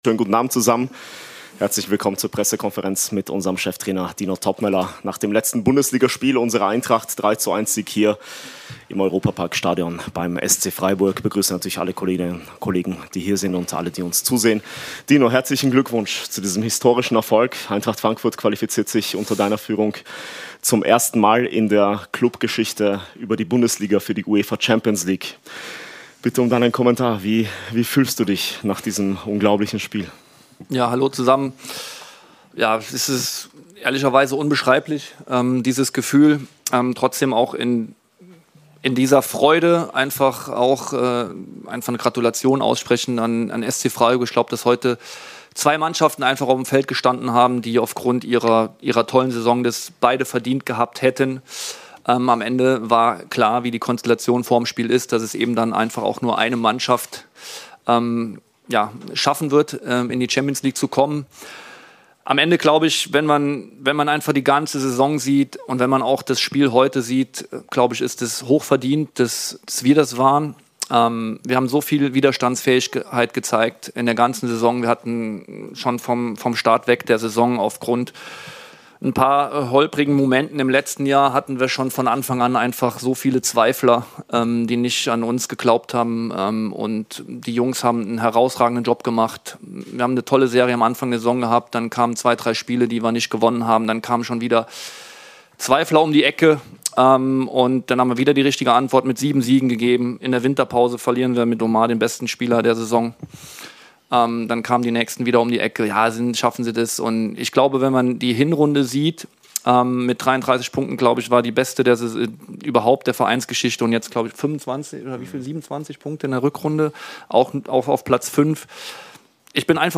Die Pressekonferenz mit unserem Cheftrainer Dino Toppmöller nach dem Auswärtssieg beim SC Freiburg.